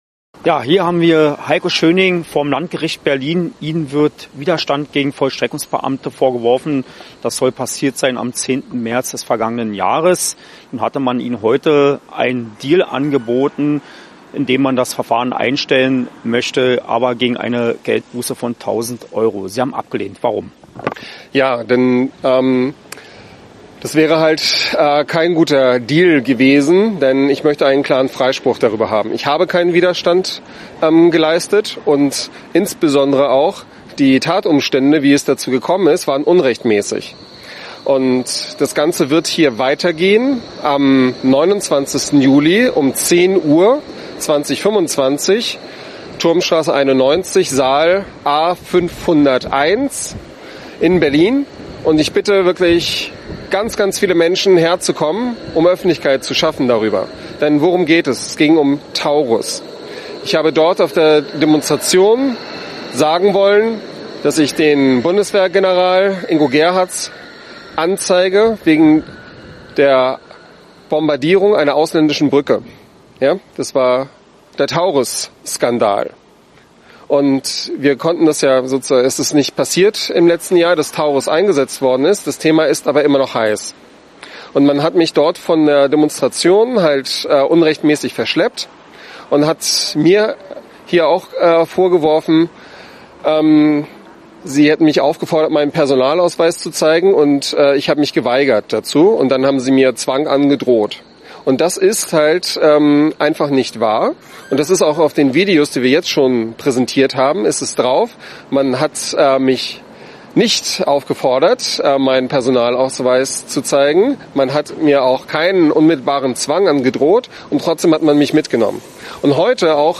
Im Gespräch mit AUF1 erhebt er